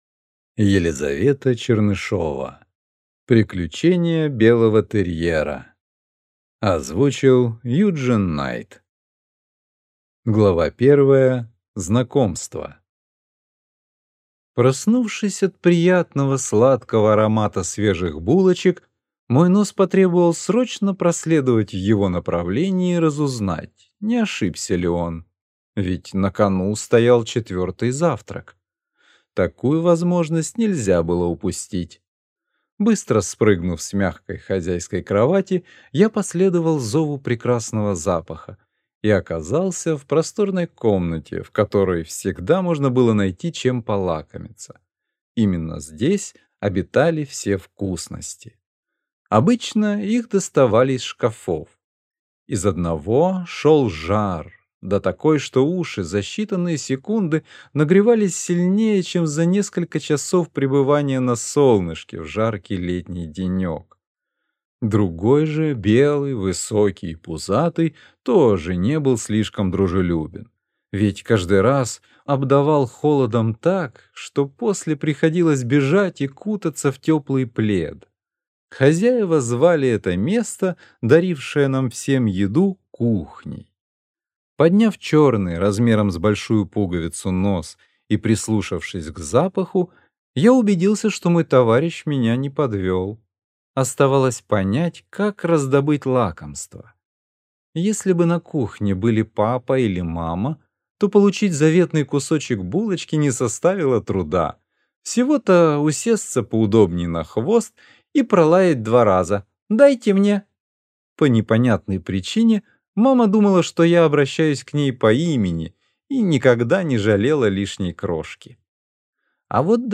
Аудиокнига Приключения белого терьера | Библиотека аудиокниг